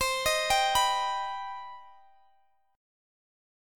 Listen to CmM7 strummed